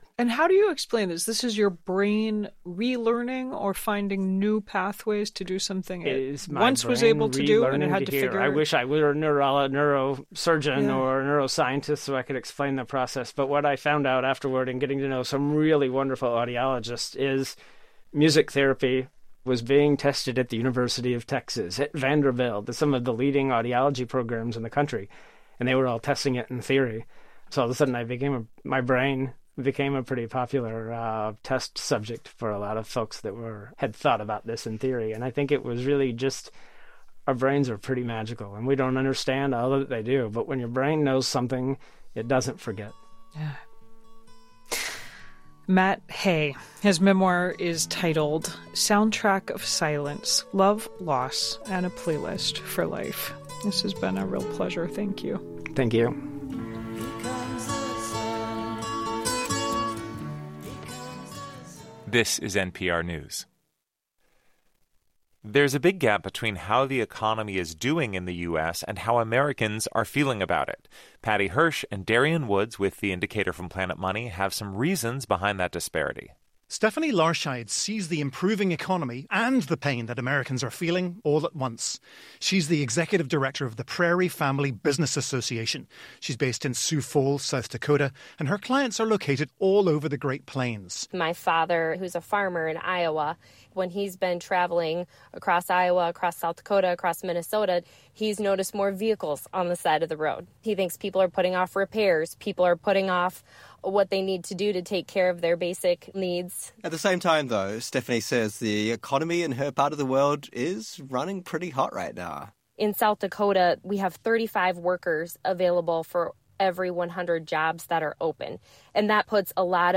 Newscast – Friday. Jan. 12 2024